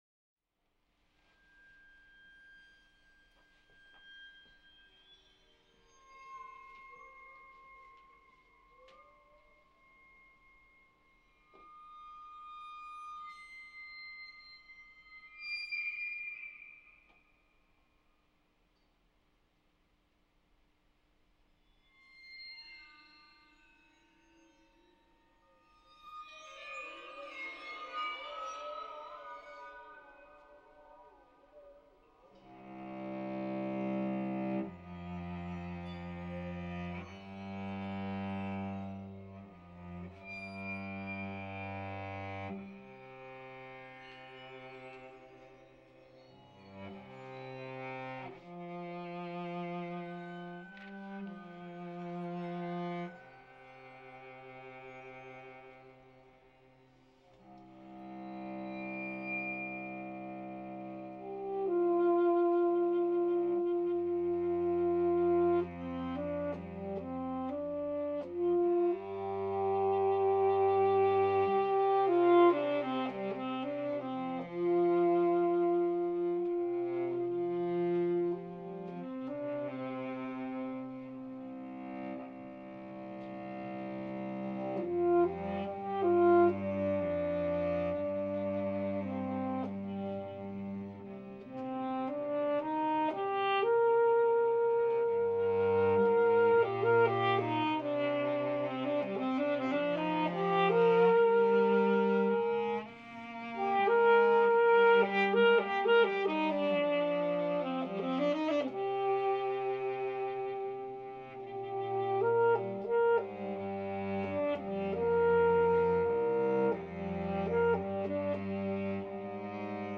MUSIC ONLY